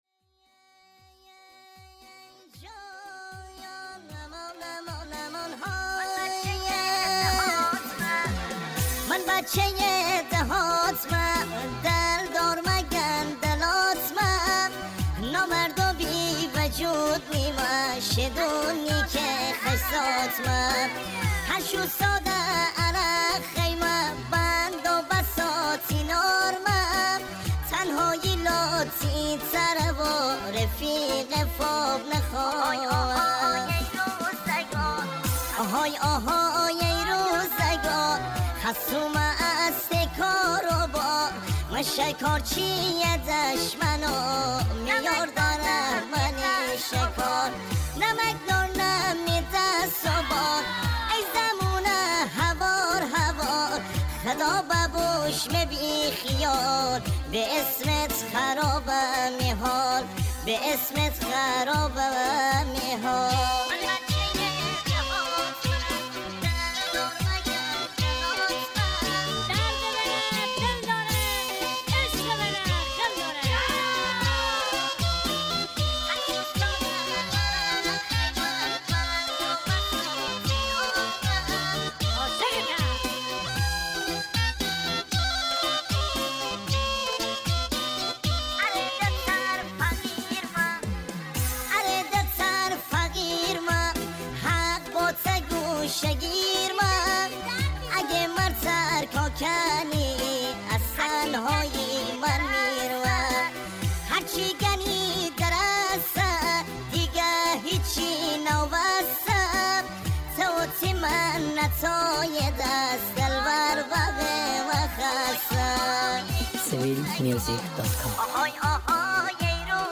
با صدای بچه نازک شده
ریمیکس مازندرانی